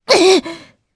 Priscilla-Vox_Damage_jp_02.wav